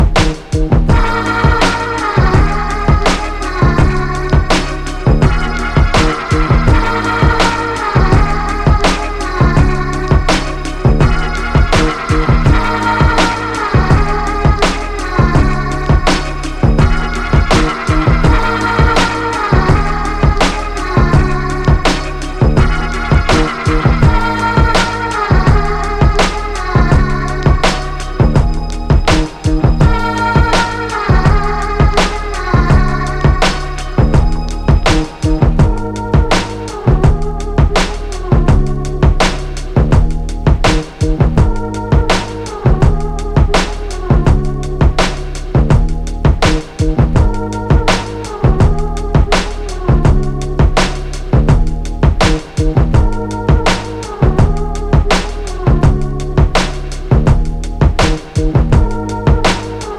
soul and jazz-inflected formula of ethereal beats
Hip hop